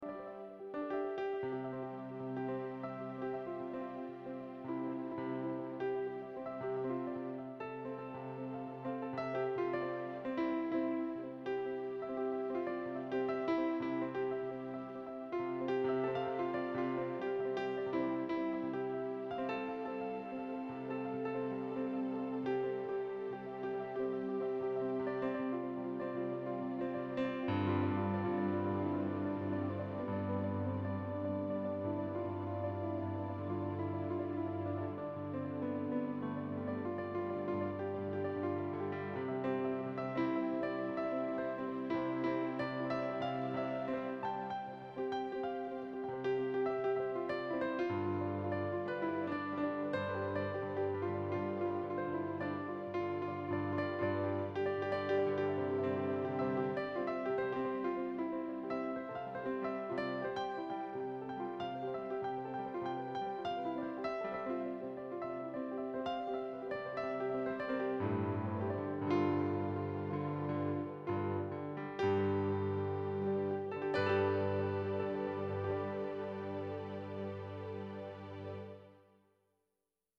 I also play & compose piano music.
These songs are instrumental ... wish they had lyrics but so far none have come to mind.
"dancing on the waves" has a swaying, wistful beauty—a farewell, a new beginning.